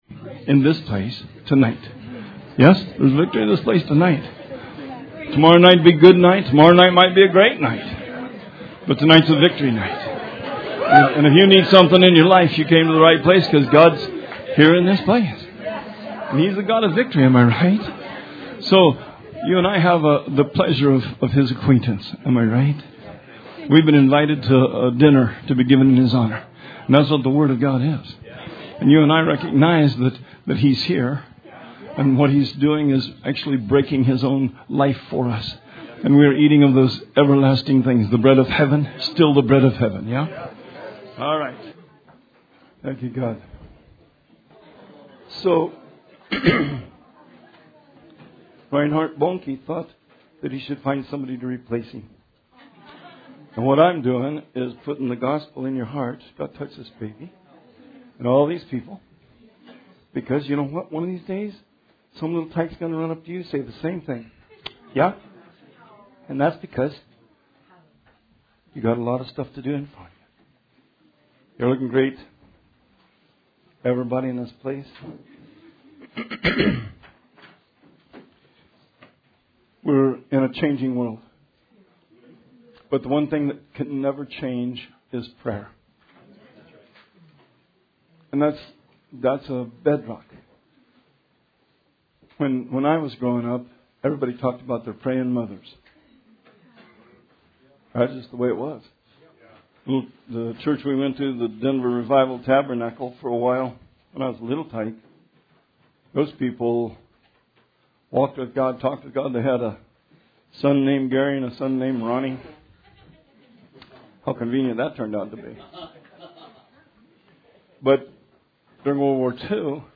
Sermon 12/29/19